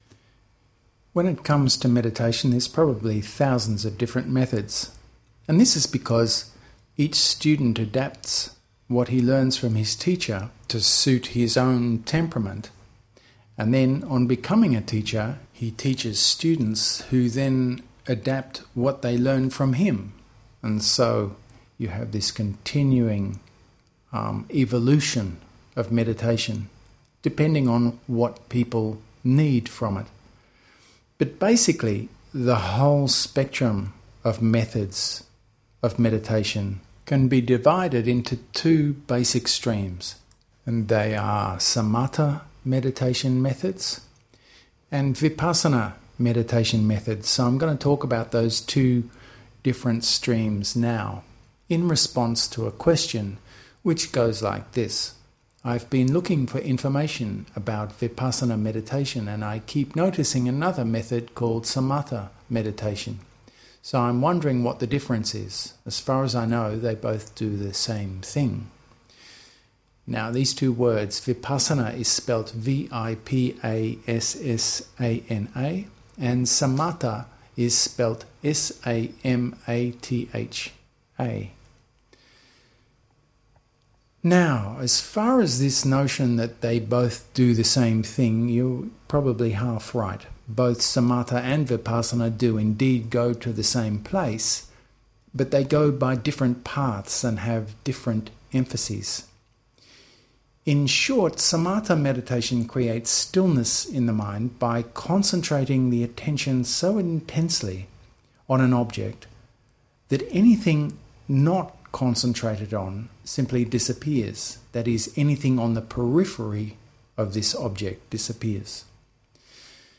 vipassana-talk-edit.mp3